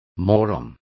Complete with pronunciation of the translation of moron.